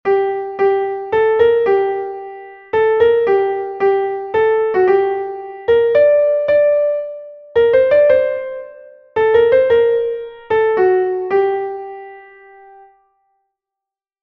Entoación a capella
Melodía 3/4 en Sol m